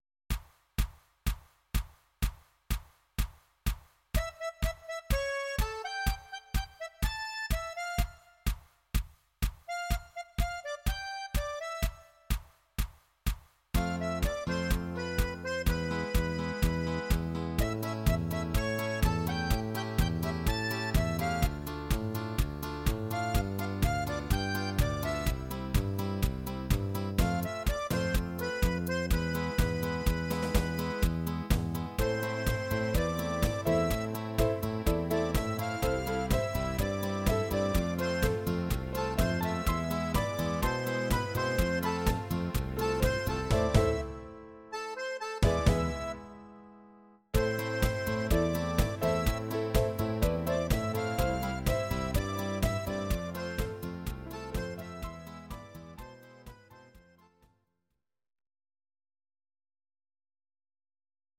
Audio Recordings based on Midi-files
Pop, Ital/French/Span, 1970s